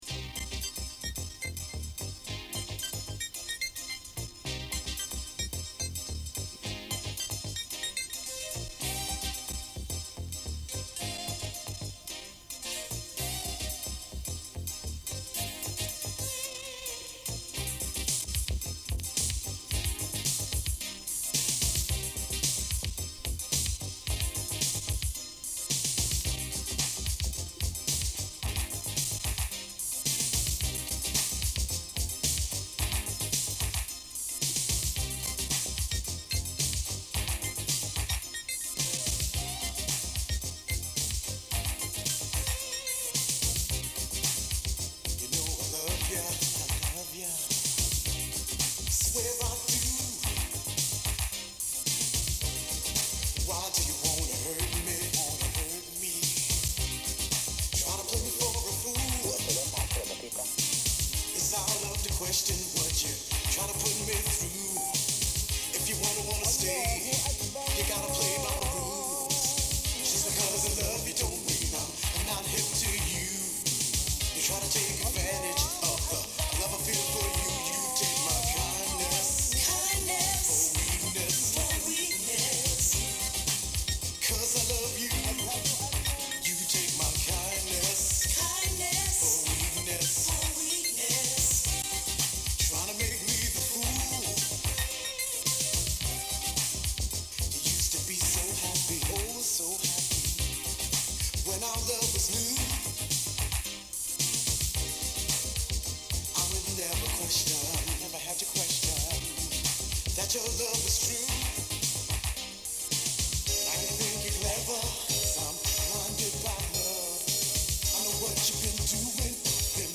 LIVE!
Originalkassette digitalisiert 2026.